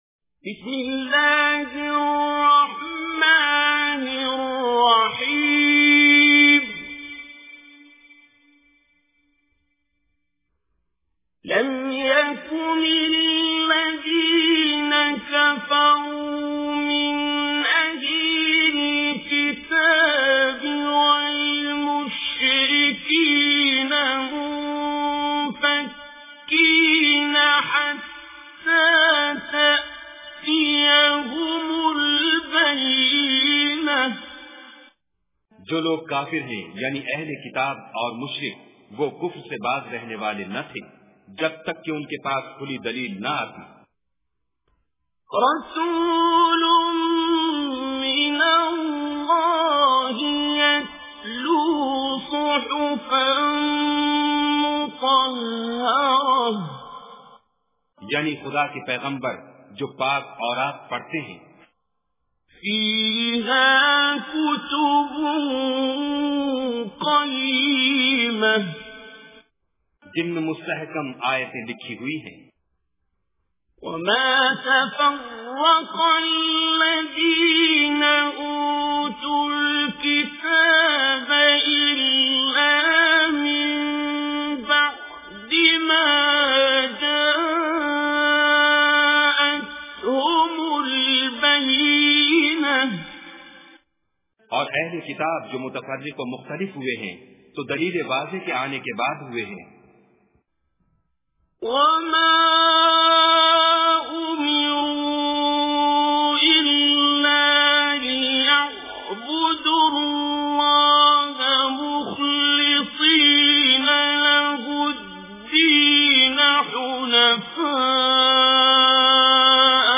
Surah Al-Bayyina Recitation with Urdu Translation
Surah Al-Bayyina is 98th chapter of Holy Quran. Listen online and download mp3 tilawat / recitation of Surah Al-Bayyina in the voice of Qari Abdul Basit As Samad.